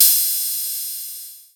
069 - Ride-2.wav